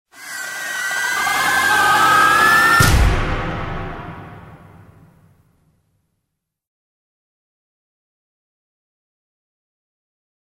Звук саспенса
Невыносимое напряжение в коротком моменте
Nevynosimoe_napriazhenie_v-kootkom_momenre.mp3